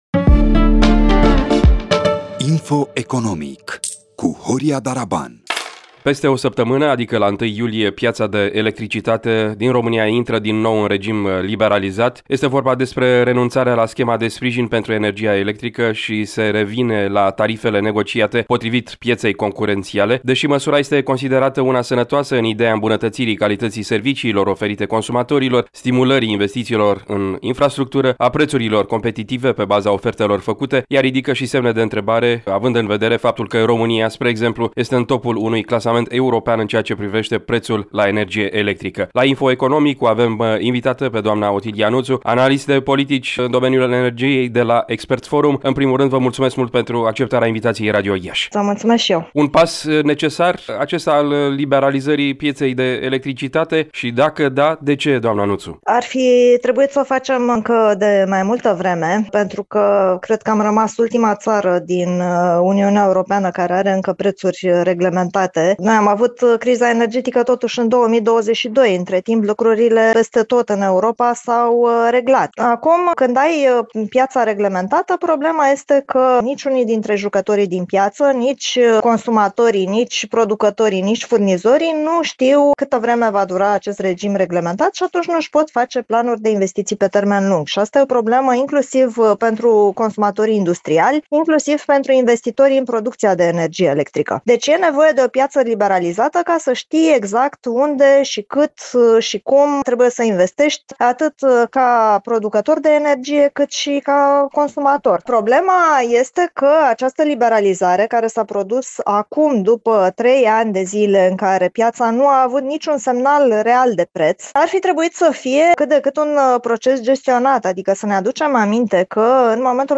Varianta audio a interviului: Share pe Facebook Share pe Whatsapp Share pe X Etichete